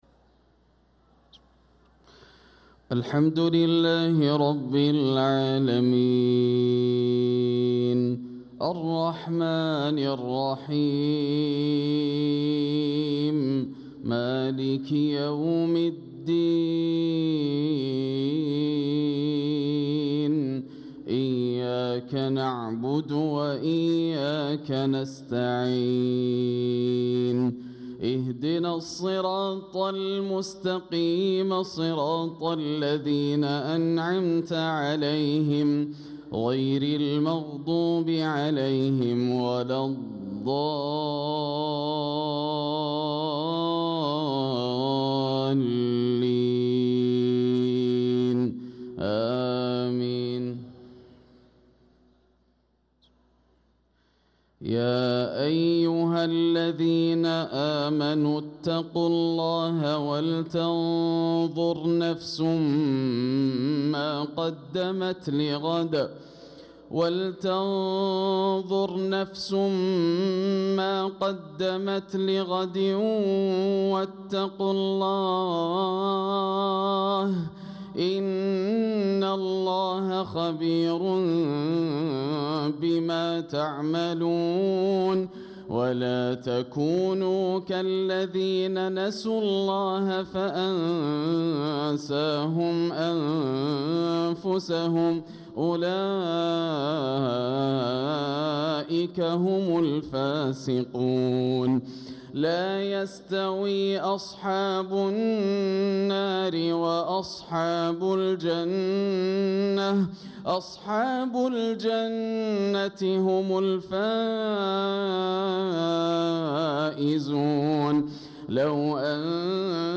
صلاة المغرب للقارئ ياسر الدوسري 13 رجب 1446 هـ